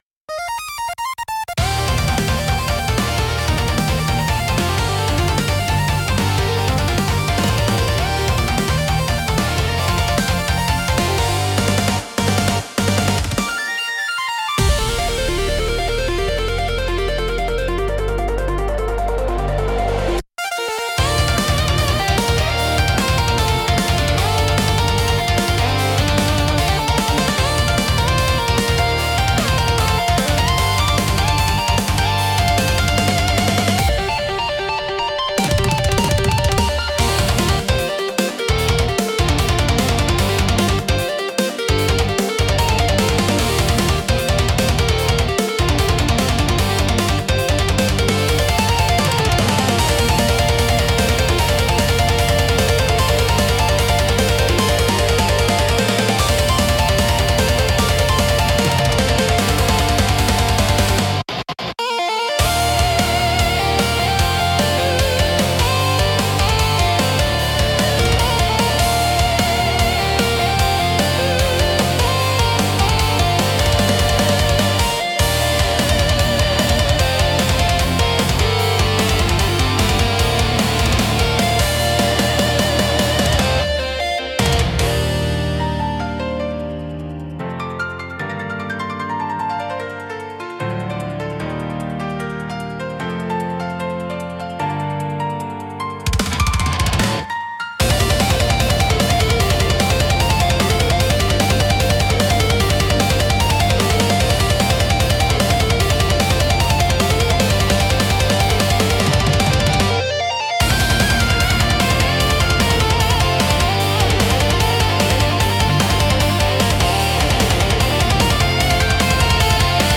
テンポの速いリズムと洗練されたサウンドで、スリルと爽快感を演出し、没入感を促進します。